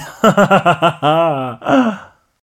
1 channel
B_RIRE_2.mp3